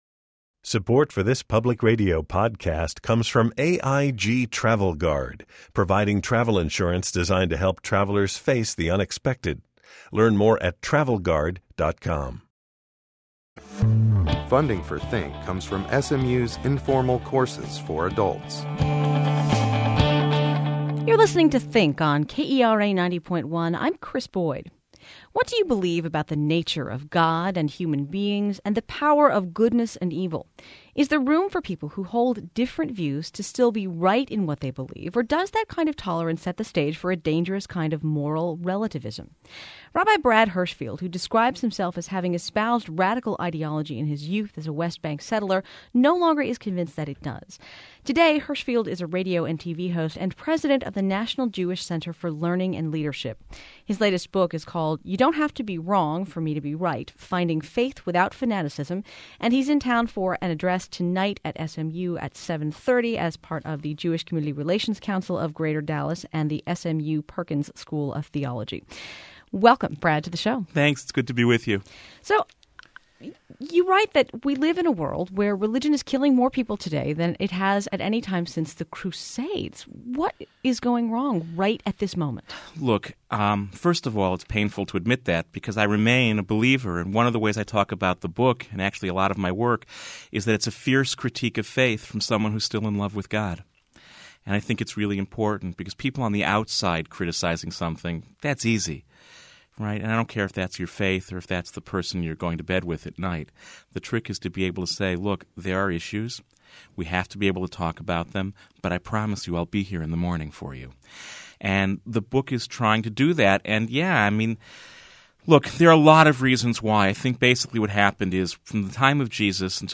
kera, npr's station in dallas, will be interviewing